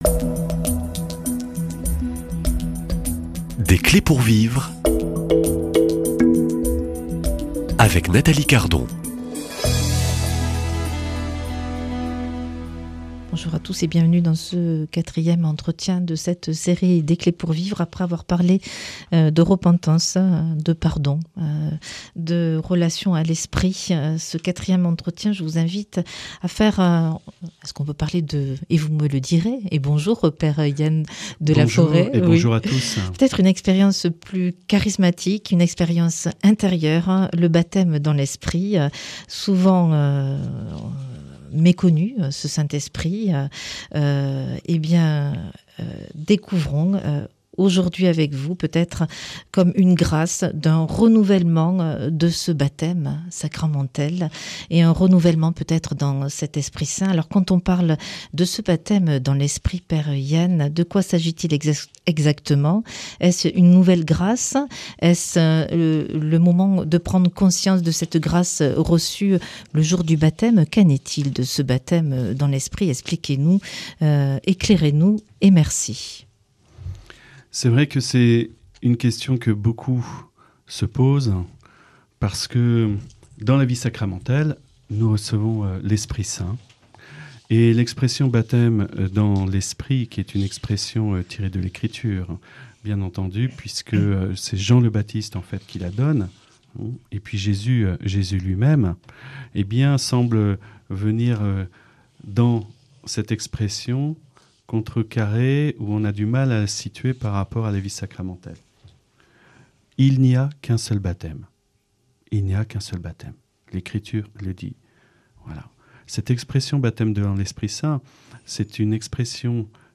Après avoir parlé de repentance, de pardon, et de relation à l’Esprit, ce quatrième entretien vous invite à une expérience plus charismatique et intérieure : le baptême dans l’Esprit.